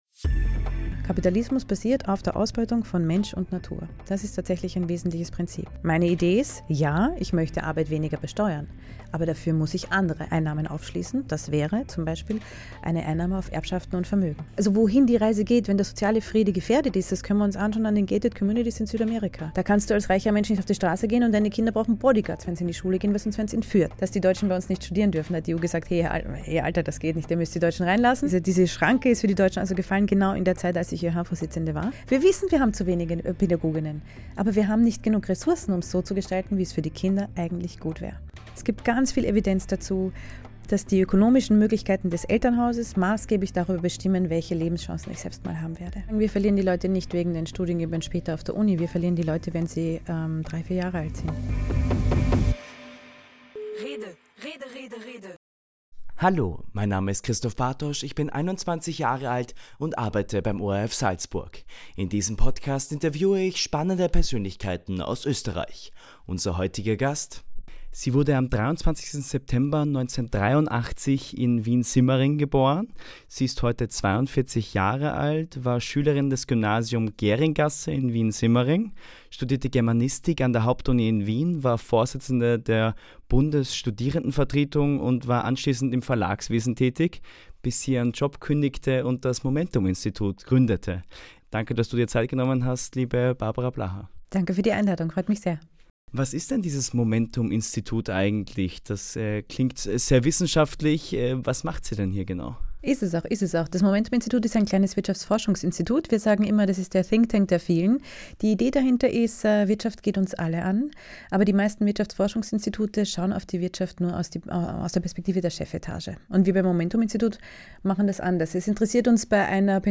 Es ist ein Gespräch über Herkunft, Haltung und die Überzeugung, dass gesellschaftlicher Fortschritt immer erkämpft werden muss.